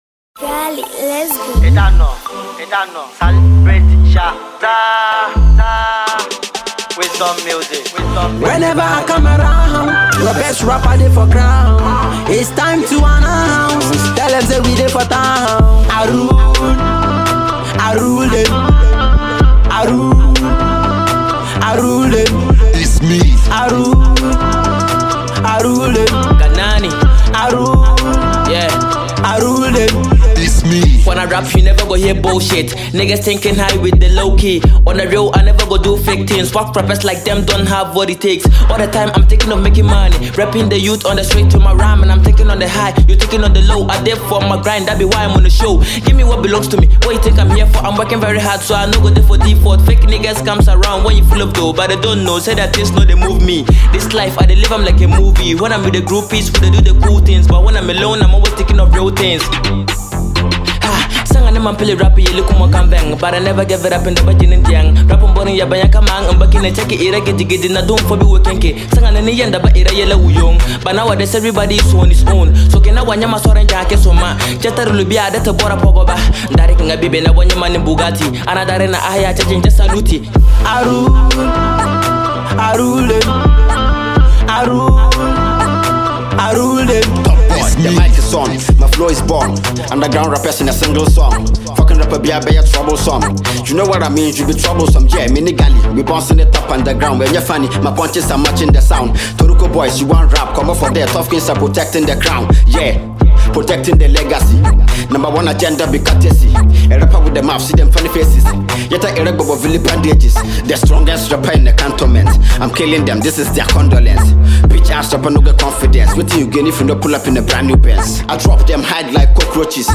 Here comes a brand new cypher